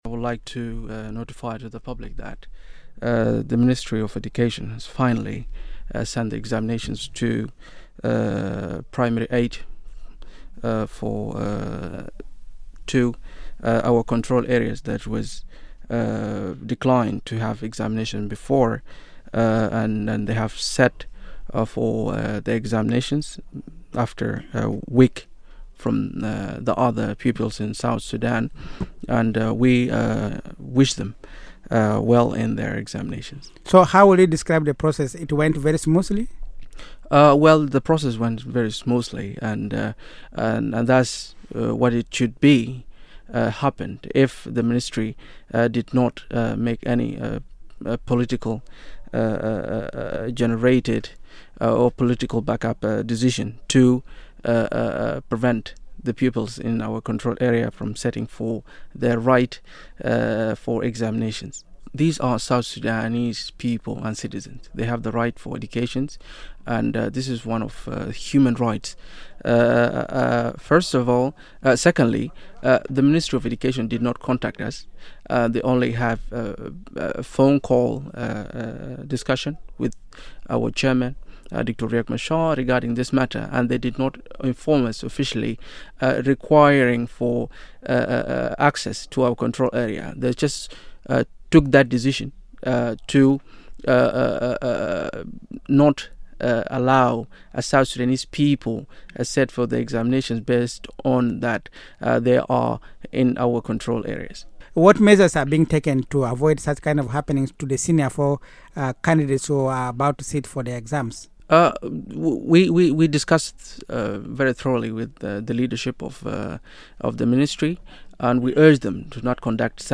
He spoke to Radio Miraya in this 4" interview.